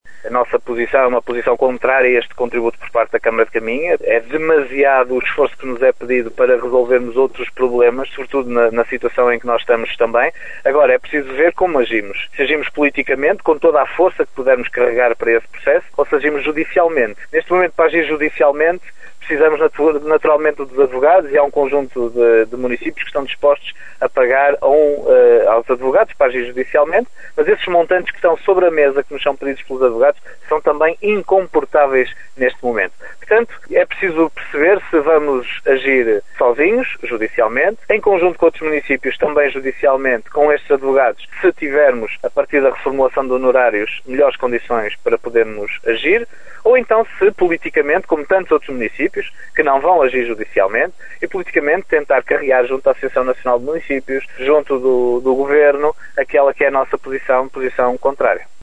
O presidente da Câmara de Caminha diz que vai decidir como é que vai contestar o FAM na próxima reunião de Câmara ordinária, ouvindo a opinião do restante executivo.